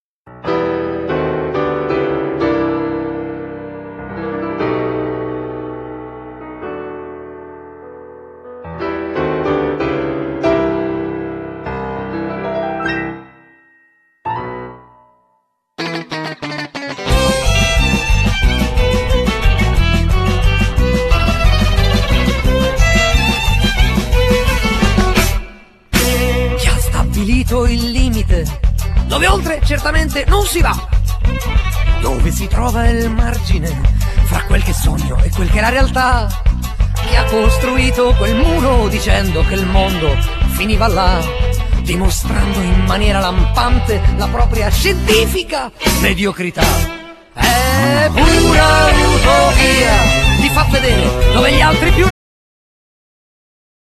Genere : Pop